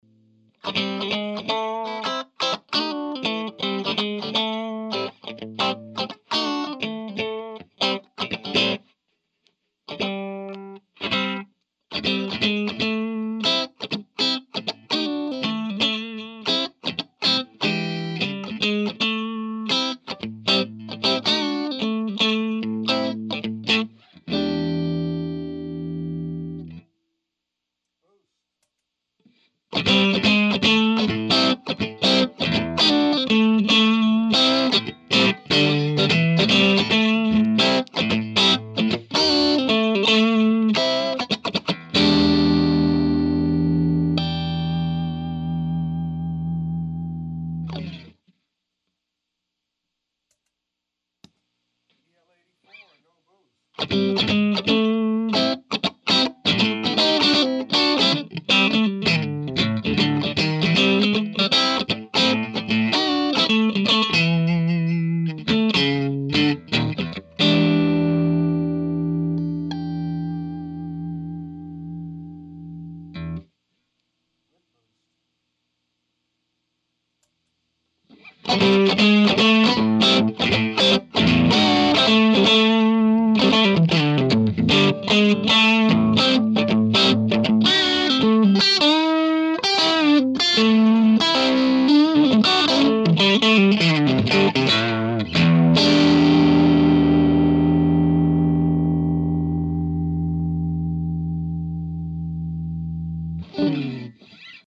For this, the first of many clips, I just stepped through the 4 modes. All controls were at 12 o'clock which fairly clean. Here is what you're hearing.
0:00 - 0:28 6v6
0:28 - 0:50 6v6 /w boost
0:50 - 1:15 el84
1:15 - End el84 /w boost
The setup was the amp into a Trinity 2x12 containing 2 tone tubby ceramics. I used a single SM-57 in front of the cab into mackie vlz preamp into protools. No fx or anything were used at anytime.
You can really get the Marshall feeling on the last part. I like to compare the 6V6 and EL84 with boost on. The EL84 with boost on has that Marshall sound to it. The boost is noticeably louder, more like a pedal.